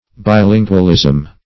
Search Result for " bilingualism" : Wordnet 3.0 NOUN (1) 1. the ability to speak two languages colloquially ; The Collaborative International Dictionary of English v.0.48: Bilingualism \Bi*lin"gual*ism\, n. Quality of being bilingual.